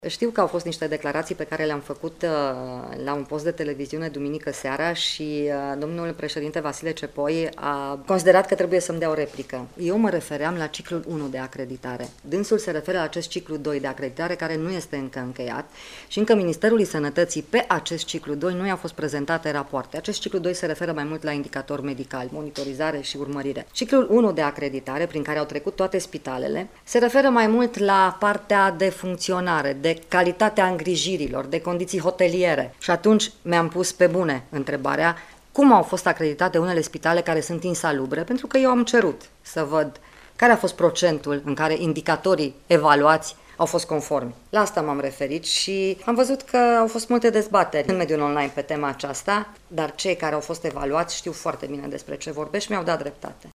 O parte dintre spitalele acreditate în România nu îndeplinesc condiţiile hoteliere impuse de Uniunea Europeană, a declarat, astăzi, la Iaşi, ministrul Sănătăţii Sorina Pintea care s-a declarat surprinsă de faptul că acestea au primit acreditare.
Sorina Pintea a adăugat că aşteaptă clarificări referitoare la acreditările primite de spitalele care nu îndeplinesc acele condiţii hoteliere necesare: